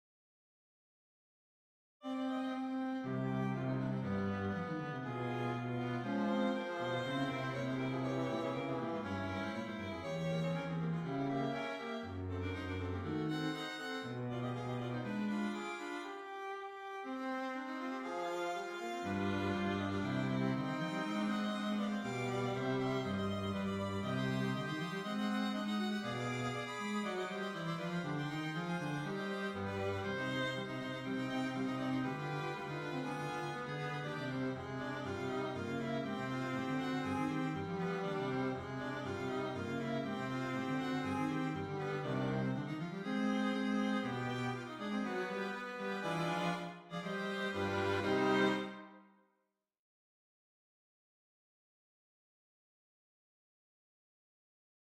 La fin de cette fugue est marquée par un caractère très dynamique donné par des batteries de doubles croches, véritable machine rythmique qui soutient mes 110 l’apparition du miroir de S en strettes. Cela est suivi d’une phrase ascendante issue de C, jouée en octaves parallèles par les deux pupitres de violons.
Les cinq dernières mesures donnent à entendre le sujet aux basses et altos un peu transformé ainsi que son inverse en strettes joué par les violons en octaves.
Mozart mélange ici la science de l’écriture contrapuntique, qu’il maîtrise parfaitement à l’expression du sentiment dramatique, renforcé d’ailleurs par la tonalité de do mineur.